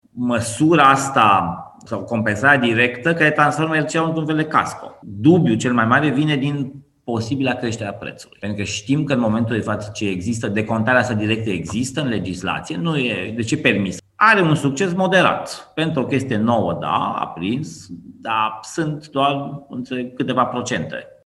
Președintele Consiliului Concurenței, Bogdan Chirițoiu: